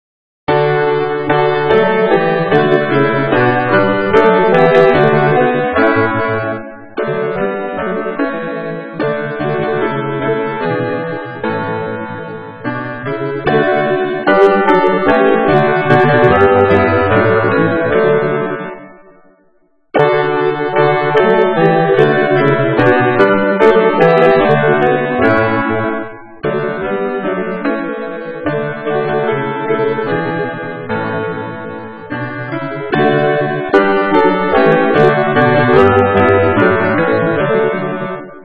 Makundi Nyimbo: Anthem | Zaburi